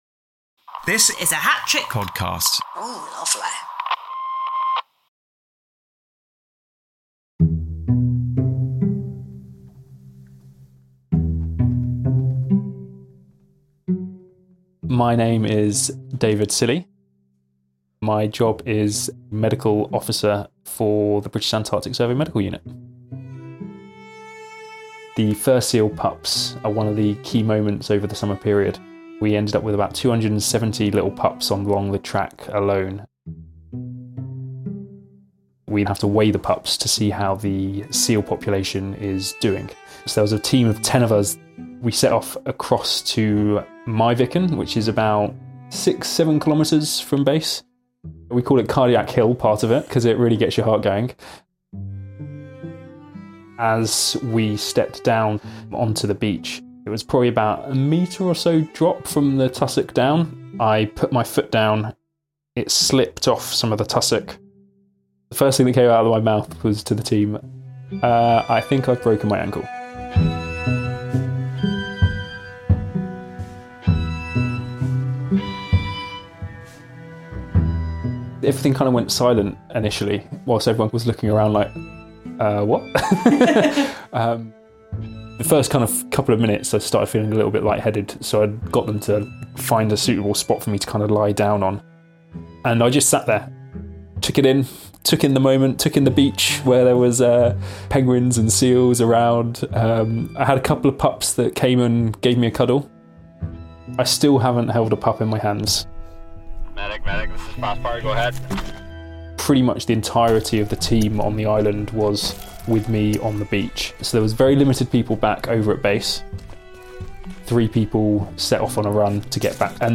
Excerpt from Boris Johnson's statement to the nation